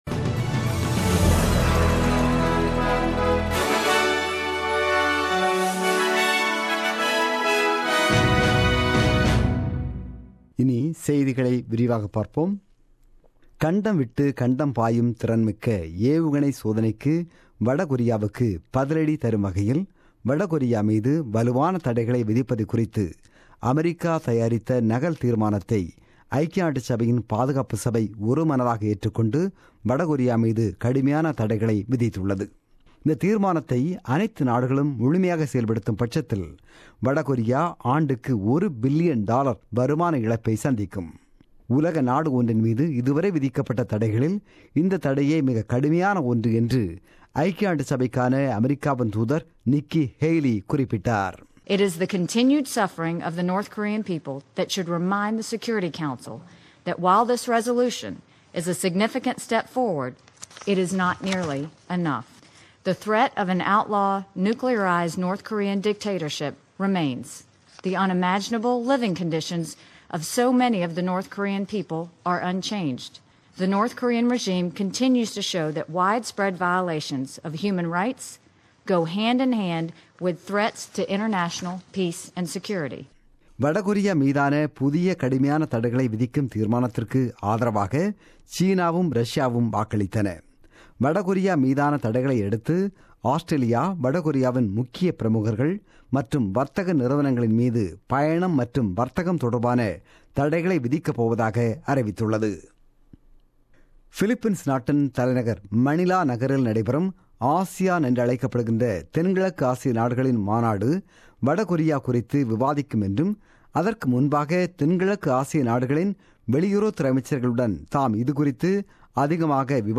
The news bulletin broadcasted on 6 August 2017 at 8pm.